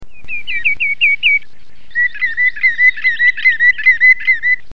Chevalier aboyeur
Tringa nebularia
aboyeur.mp3